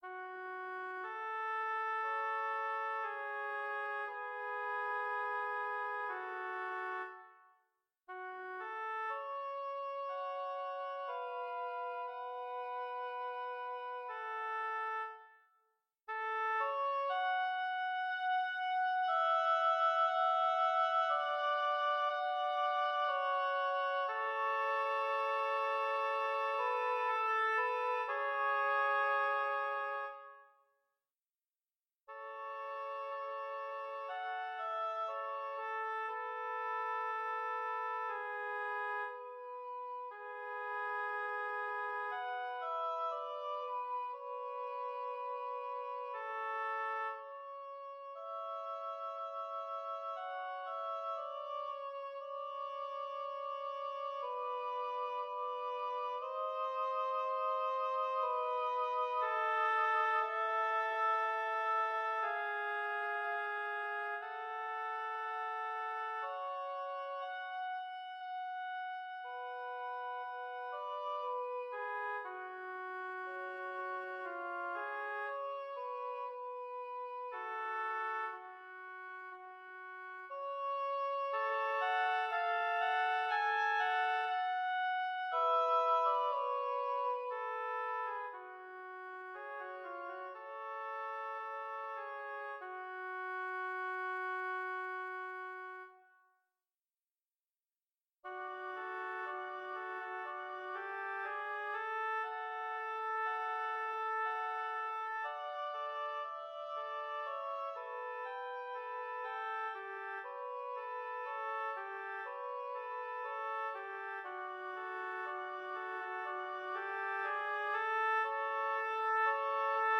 Intermediate oboe duet